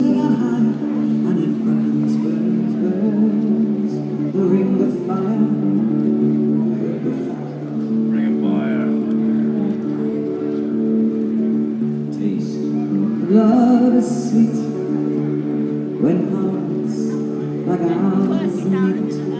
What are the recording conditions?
Amazing busker in Redditch.